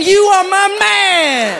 Category: Comedians   Right: Both Personal and Commercial
Tags: aziz ansari aziz ansari comedian